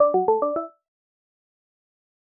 MINI_ringtone.wav